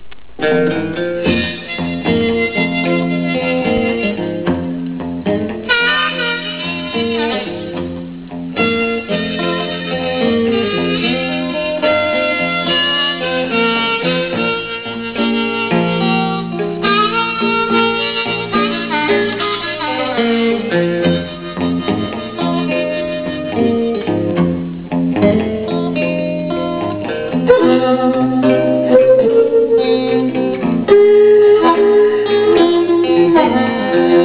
Later he brought us into a studio in Brooklyn, and we recorded this and